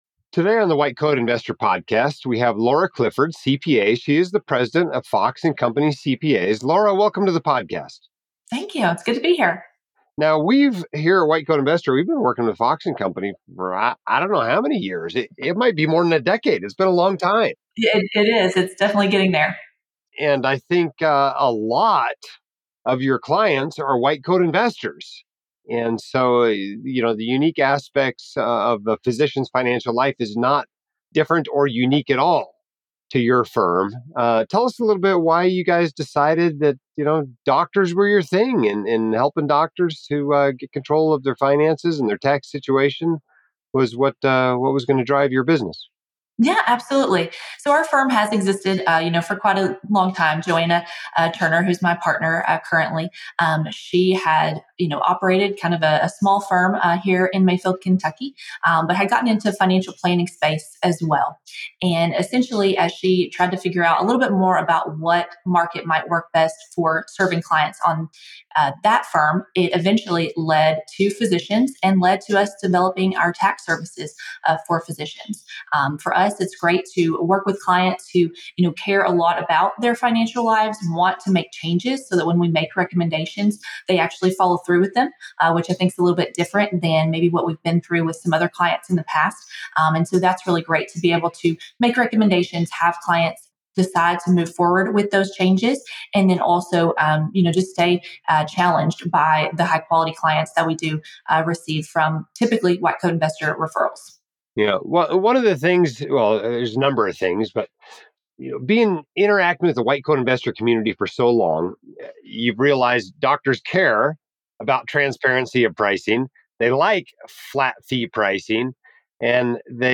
Latest WCI Podcast Appearance